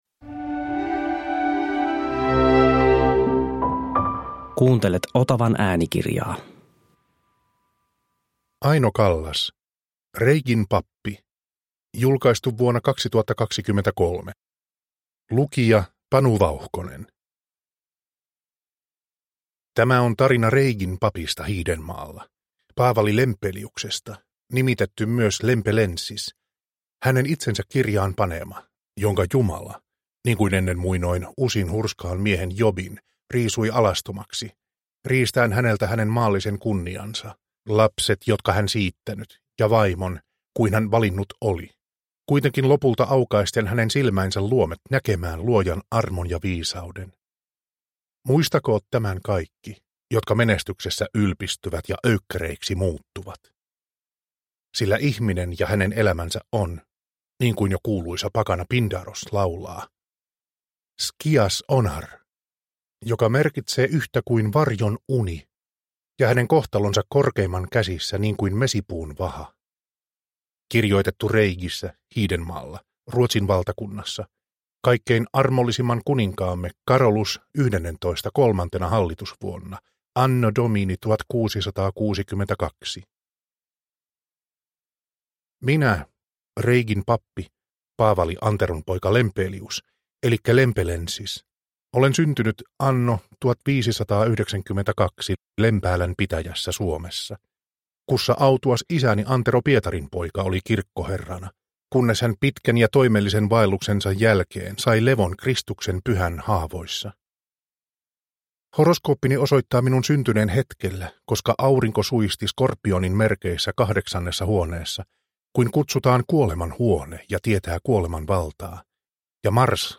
Reigin pappi – Ljudbok – Laddas ner